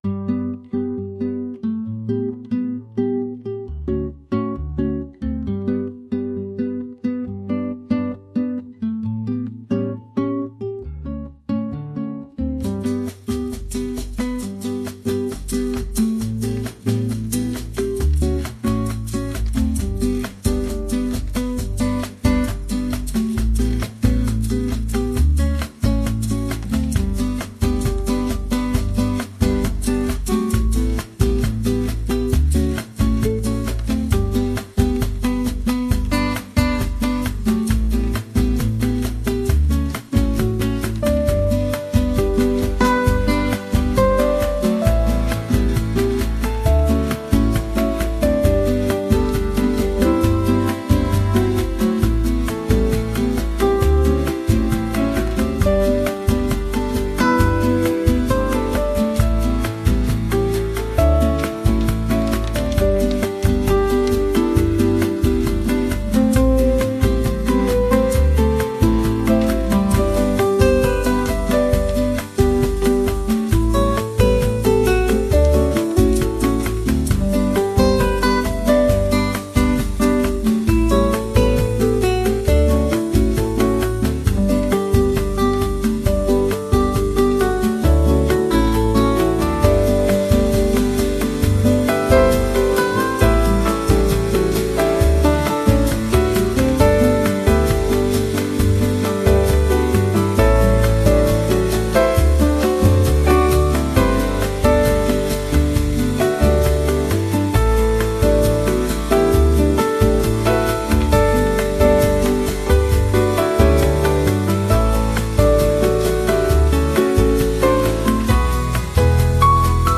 ・メインセクションは、アコースティックギターとピアノが中心となり、リズミカルなボサノバのビートが曲の進行を支えます。